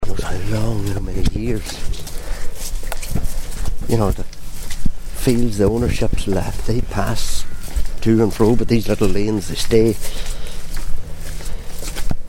out and about in Inishowen